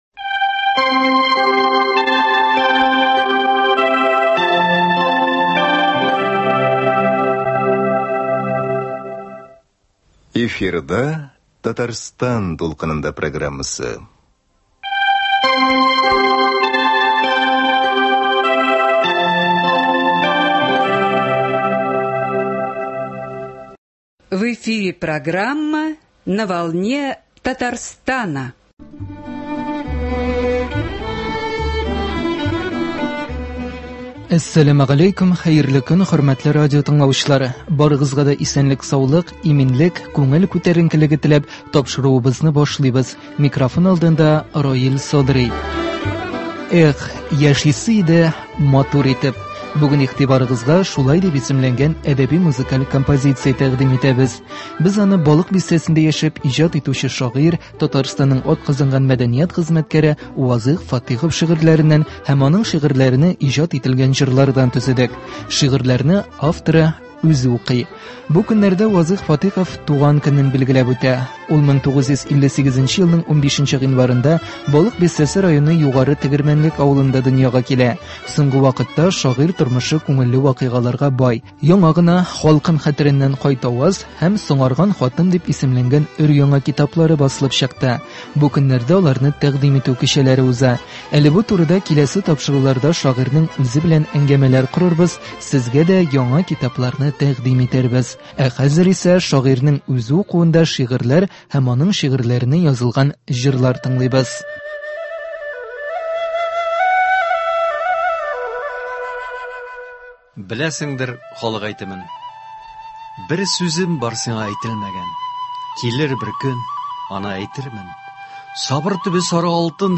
Бүген игътибарыгызга шулай дип исемләнгән әдәби-музыкаль композиция тәкъдим итәбез.